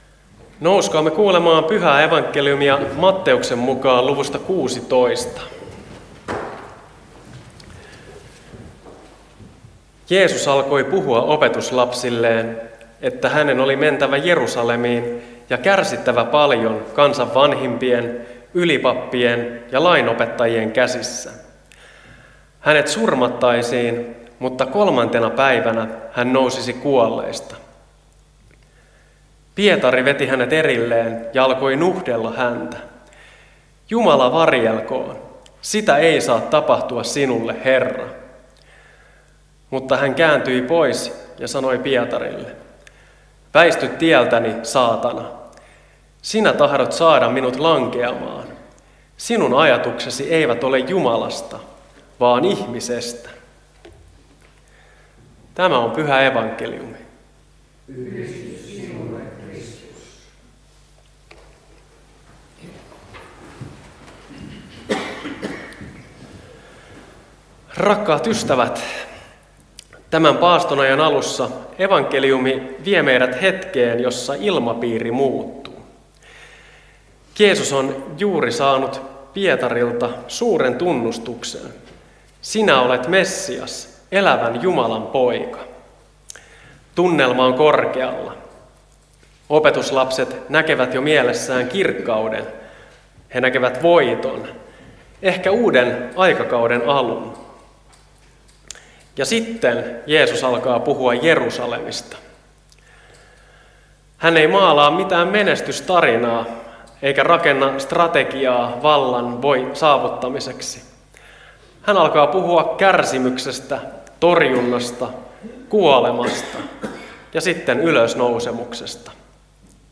saarna Lohtajalla 1. paastonajan sunnuntaina Tekstinä Matt. 16:21–23